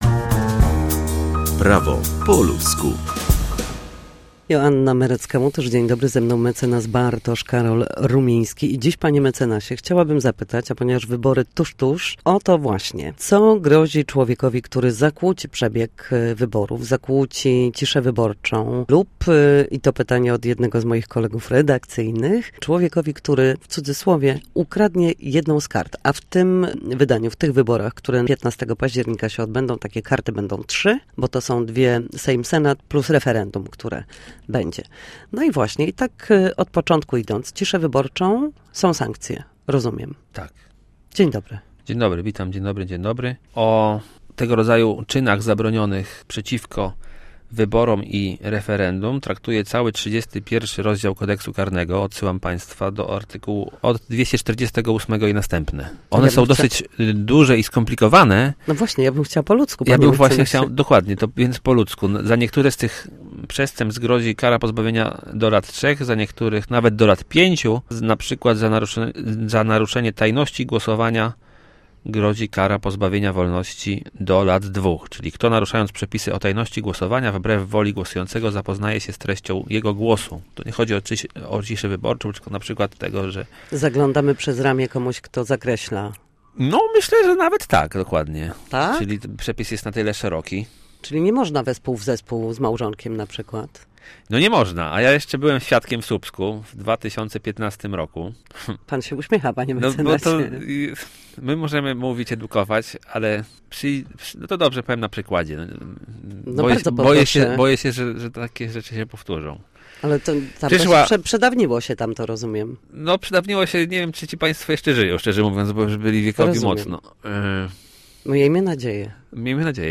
Cisza wyborcza i łamanie przebiegu wyborów. O prawnych aspektach elekcji rozmawiamy w Studiu Słupsk